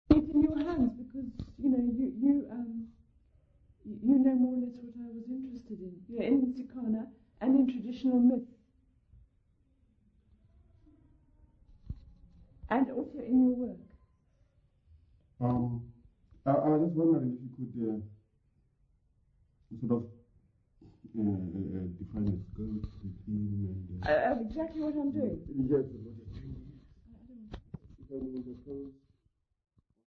Interviews South Africa
Africa South Africa Alice, Eastern Cape sa
field recordings